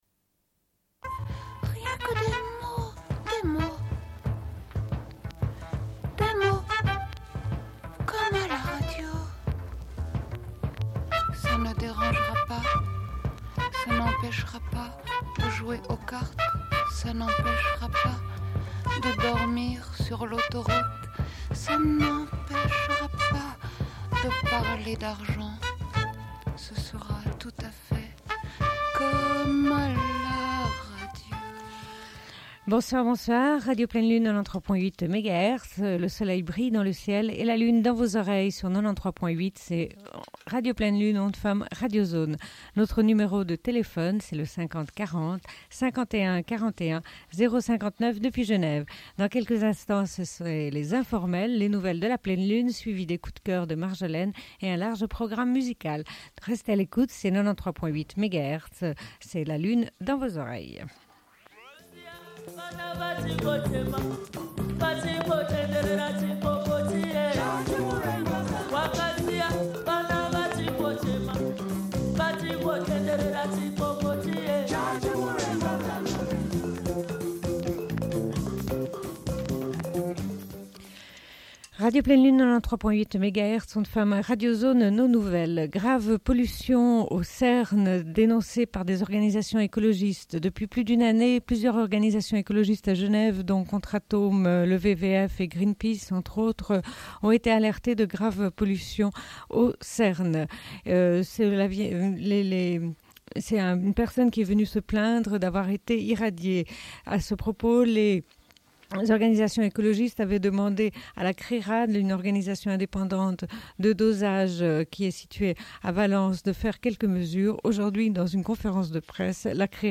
Bulletin d'information de Radio Pleine Lune du 24.04.1996 - Archives contestataires
Une cassette audio, face B